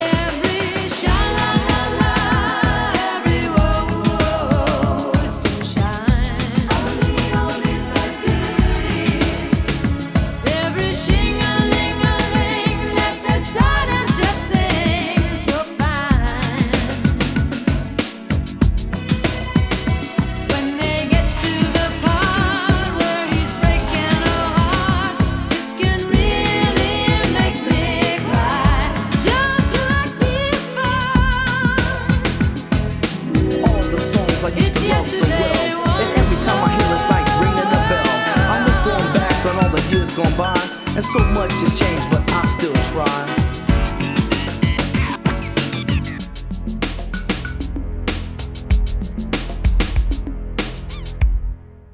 поп
женский вокал
спокойные
90-е
танцевальная музыка